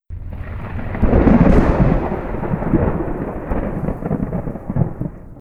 thunder3.wav